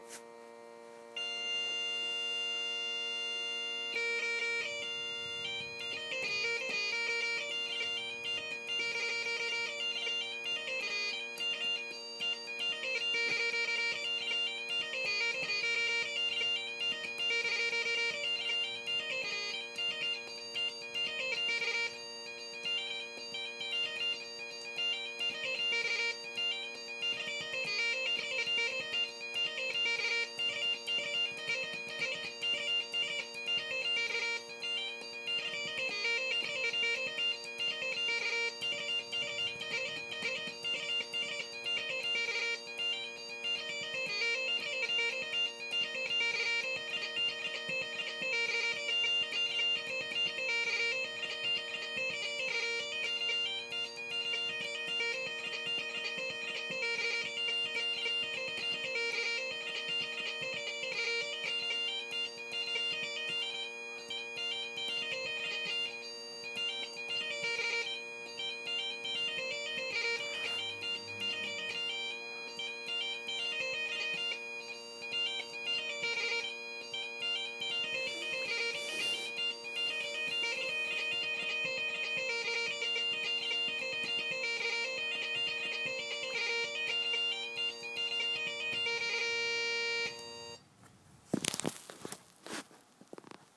North West Bagpiper
haste-to-the-wedding-jig.m4a